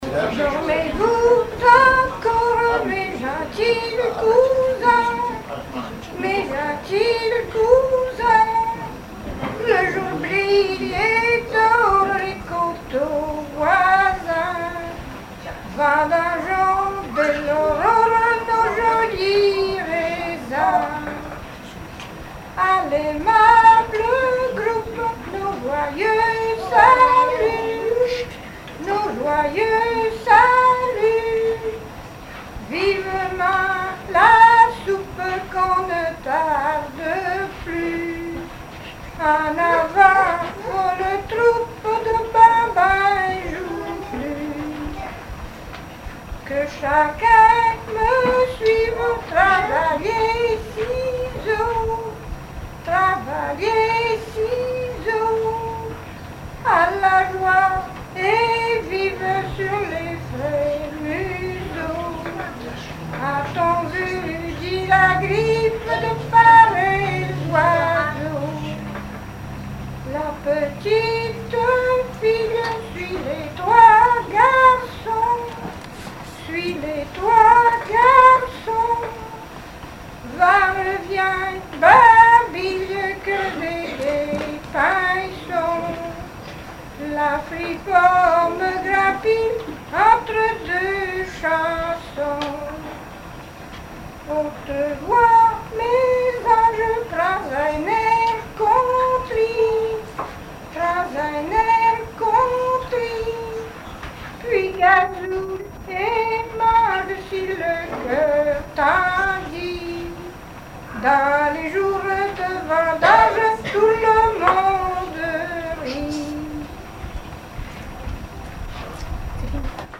Genre strophique
Chansons traditionnelles
Pièce musicale inédite